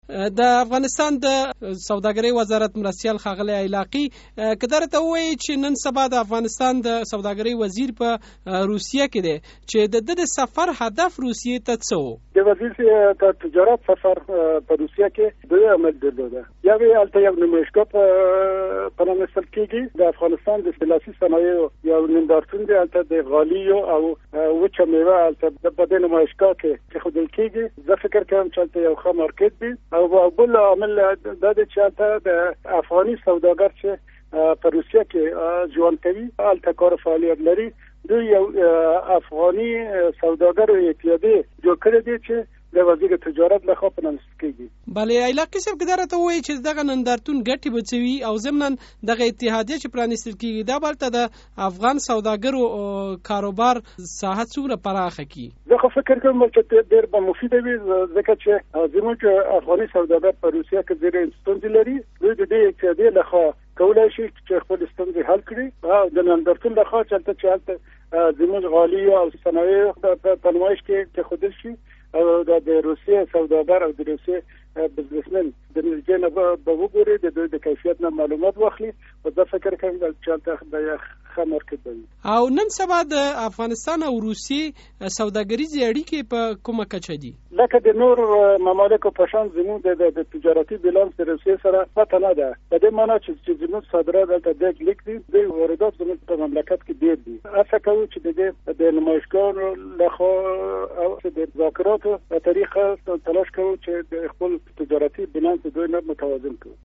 د سوداګرۍ وزارت له مرستیال غلام محمد ایلا قي سره مرکه